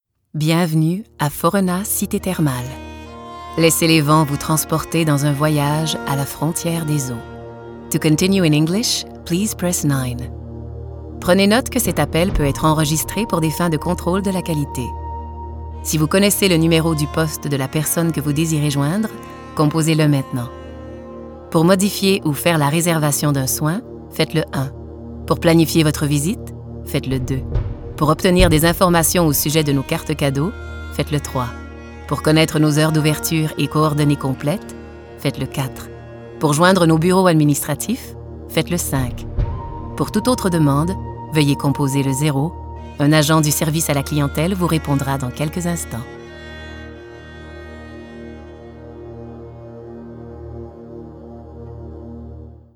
Message téléphonique